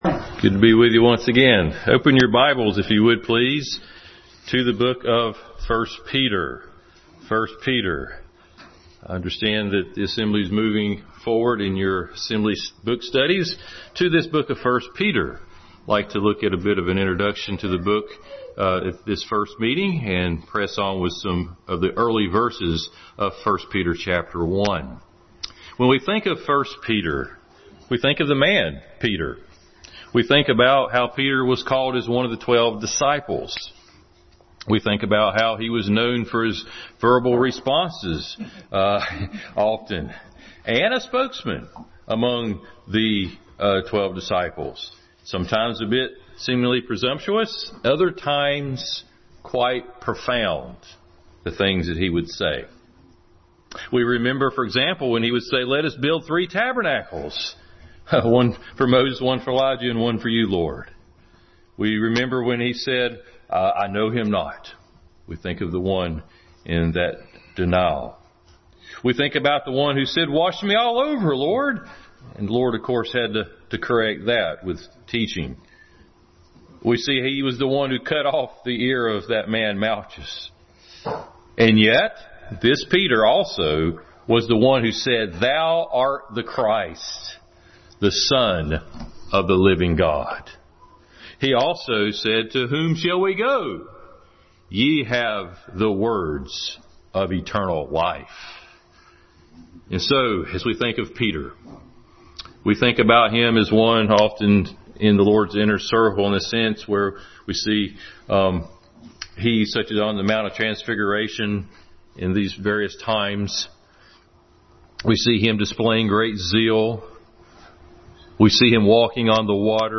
1 Peter Introduction Passage: 1 Peter 1:10-11, 2:19-25, 3:13-18, 4:1-3, 13, 19, 5:4 Service Type: Sunday School